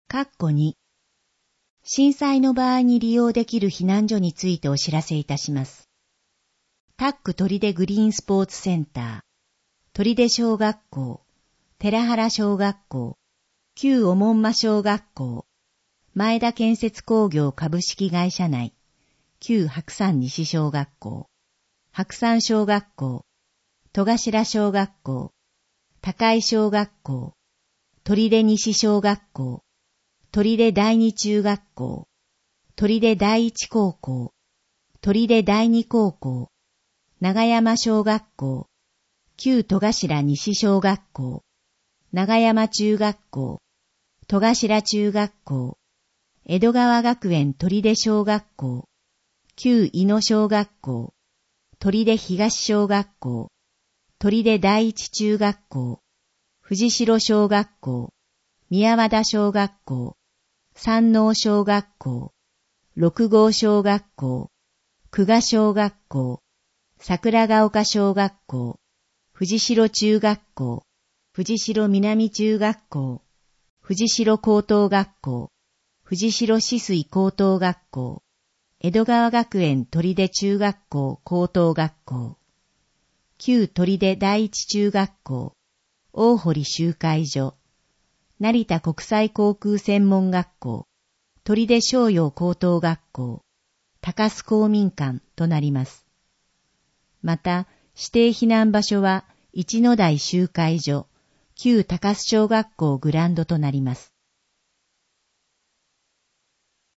総合防災マップの内容を音声で聞くことが出来ます。音声データは市内のボランティア団体、取手朗読奉仕会「ぶんぶん」の皆さんのご協力により作成しています。